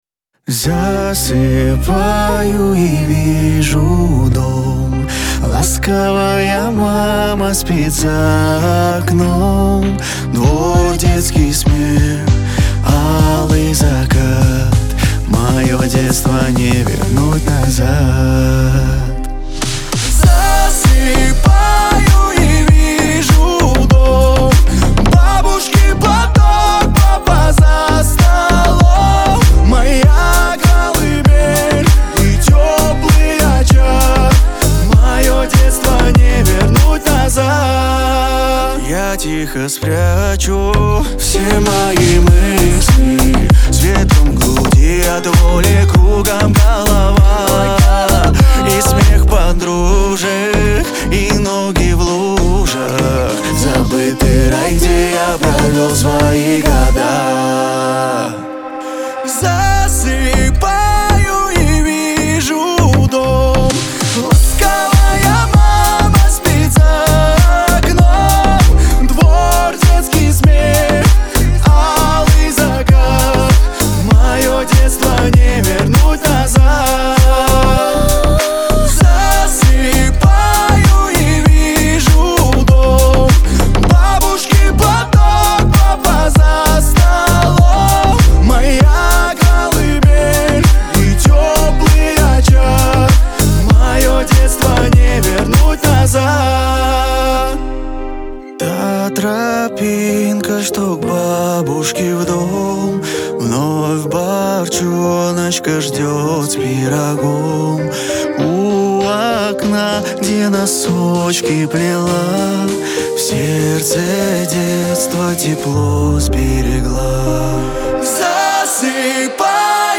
грусть
Шансон